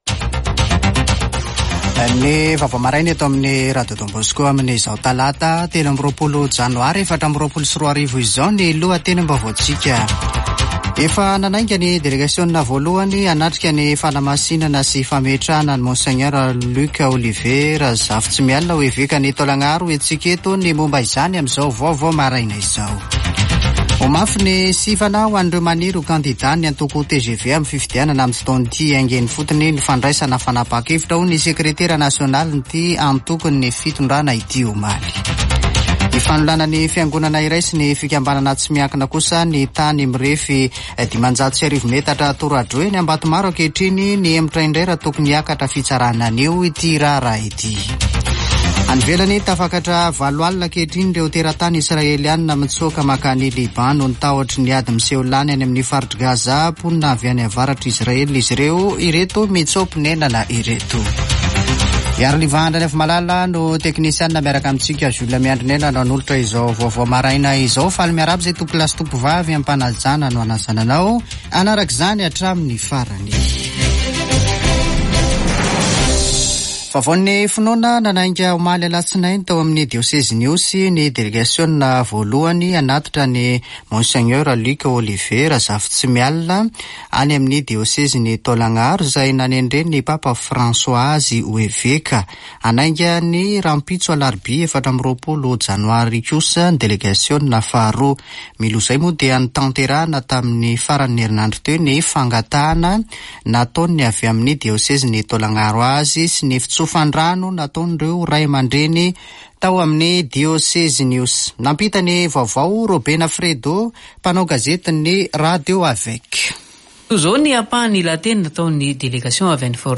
Lohahevitra : Vaovao